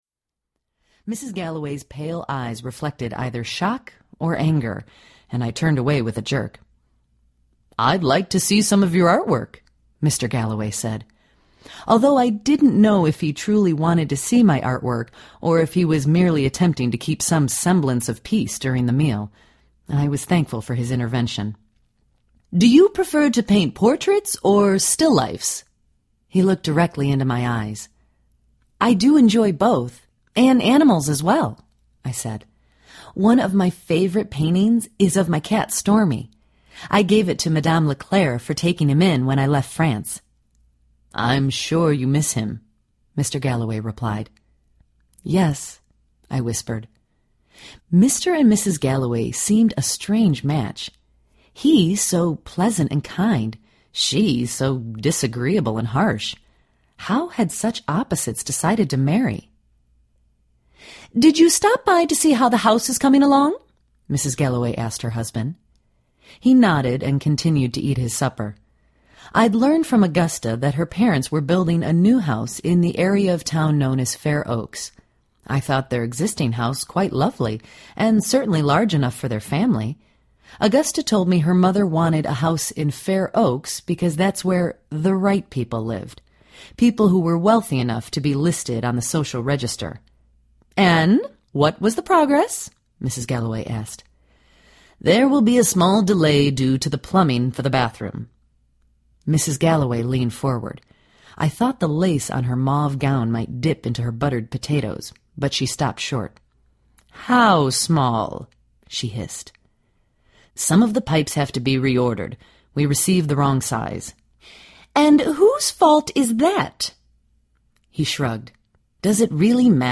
The Carousel Painter Audiobook
8 Hrs. – Abridged